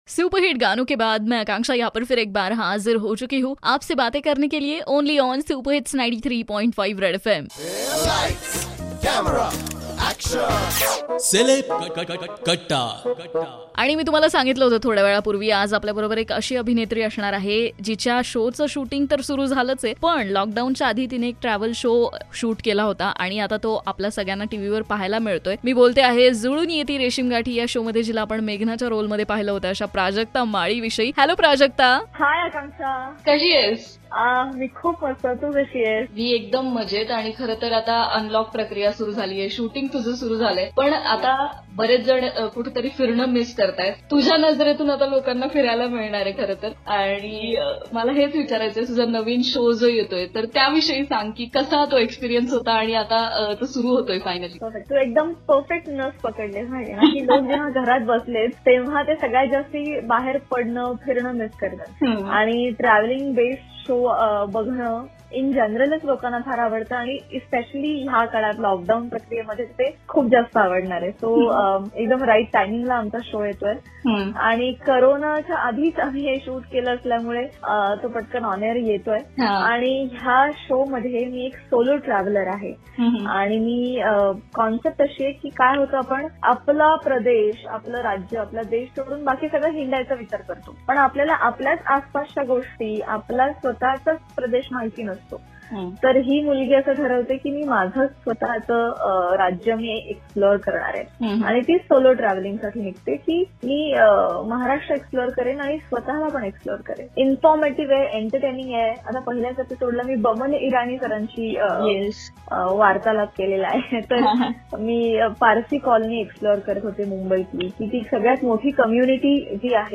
took an interview of famous actress Prajakta Mali,about her new travel show